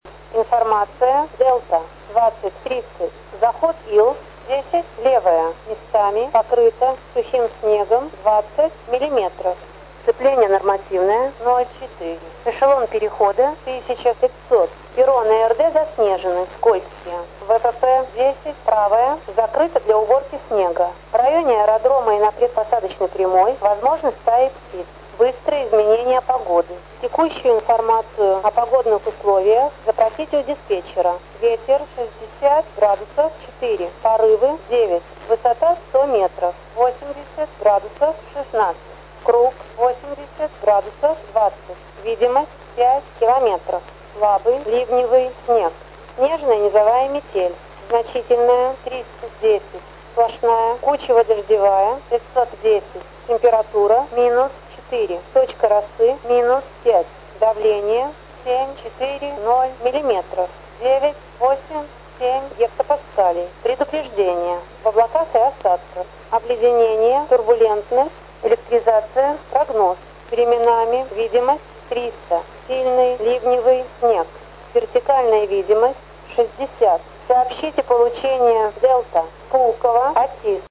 АТИС аэропорта Пулково 09.12.2010г.
Начало » Записи » Записи радиопереговоров - авиация
Пулково-АТИС, запись 09.12.2010г.
pulkovo_atis.mp3